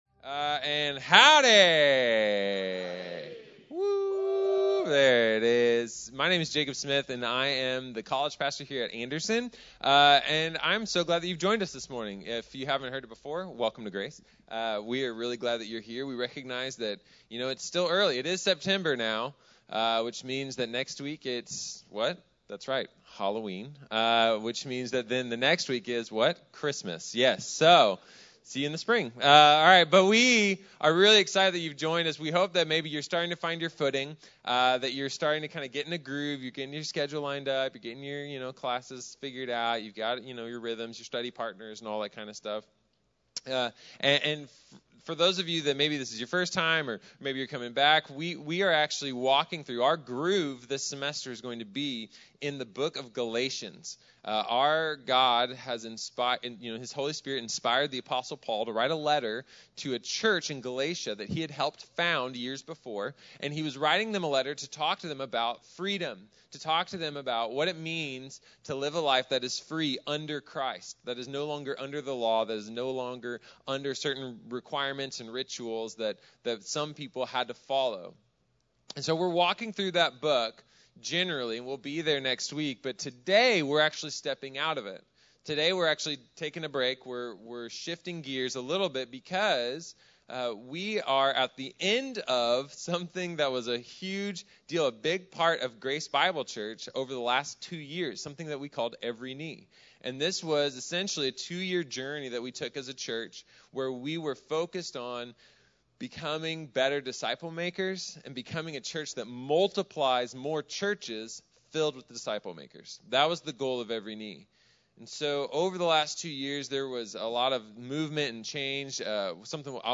Cada Rodilla | Sermón | Iglesia Bíblica de la Gracia